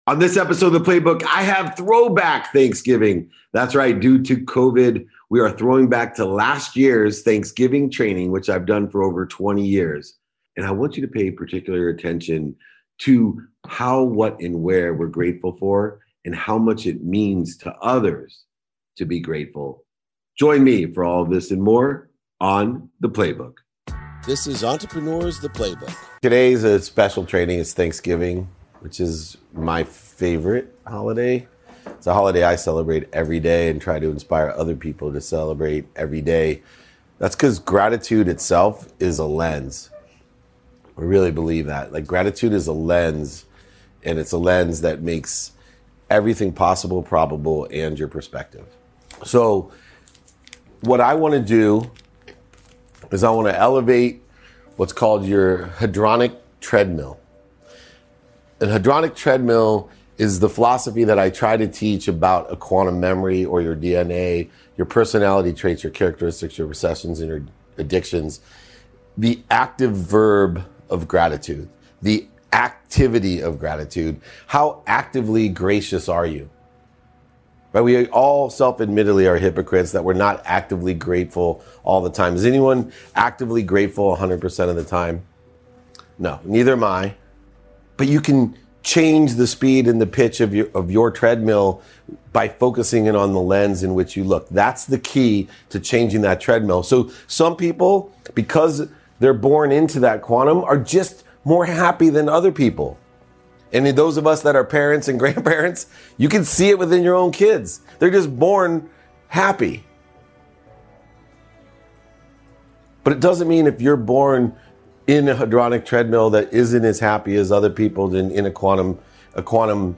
Today's episode is a Throwback to my Thanksgiving Training from last year. Last year I had everyone write down the most inspirational person, place, or thing in their lives.